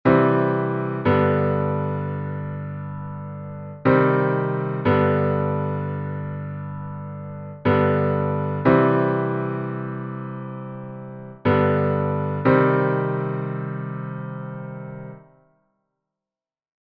Im engeren Sinn versteht man jedoch unter Ganzschluss die Folge Dominante - Tonika, unter Halbschluss Tonika - Dominante:
Halb- und Ganzschluss
Halbschluss versus Ganzschluss, Notenbeispiele
ganzschluss-riemann1882.mp3